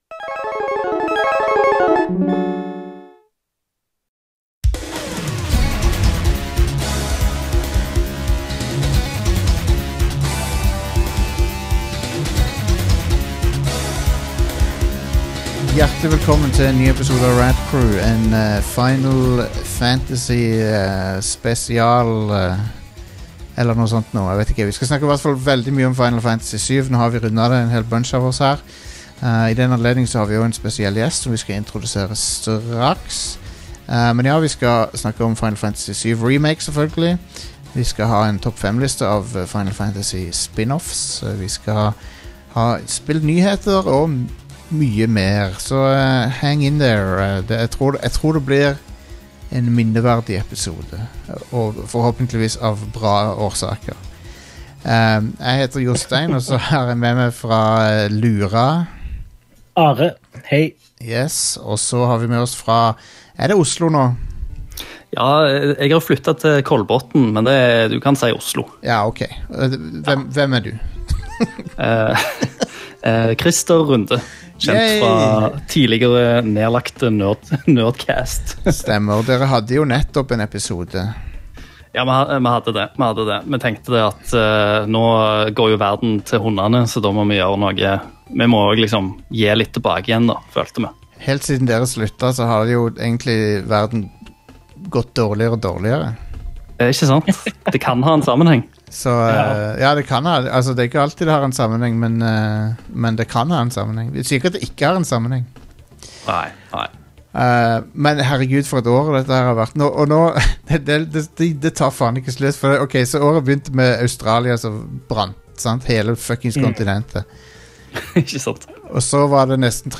Vi ber om tålmodighet for enkelte tekniske utfordringer mens vi tilpasser oss, inkludert ujevn lyd her og der.